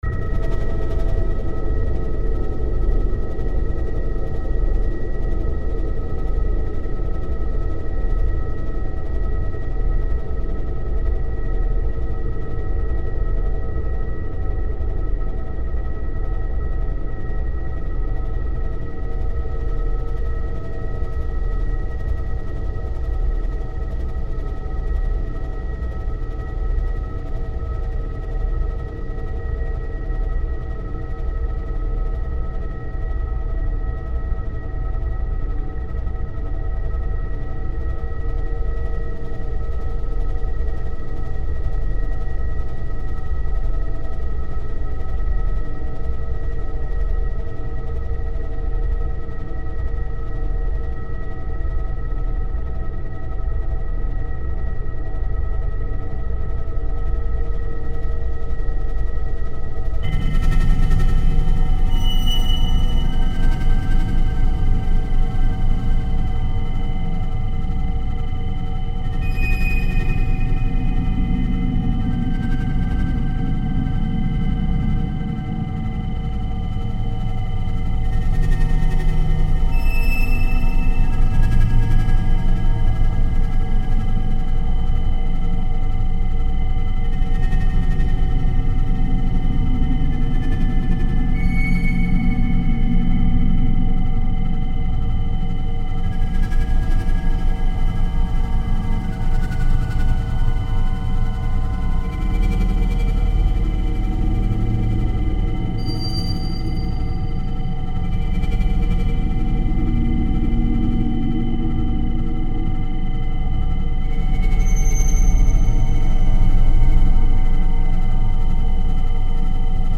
This "archive" consists music in dark ambient style for old project "North Wind".